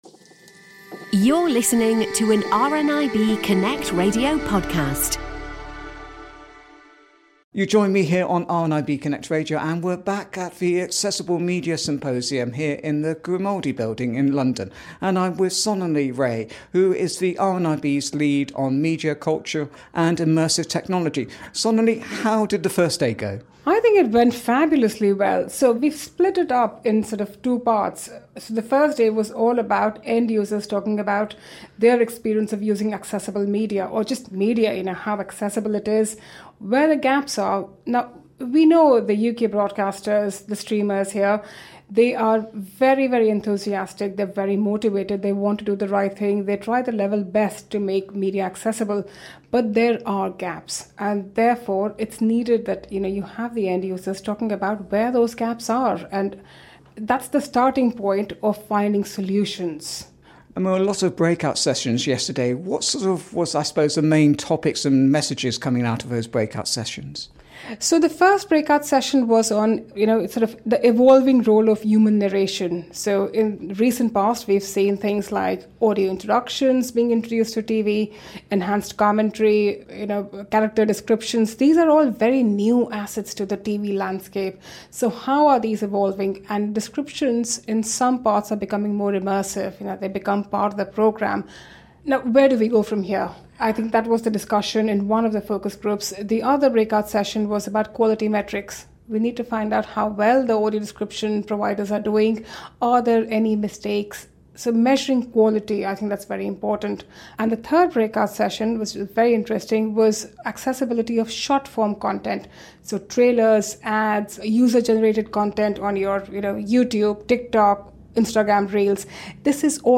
(Image shows the RNIB Connect Radio logo.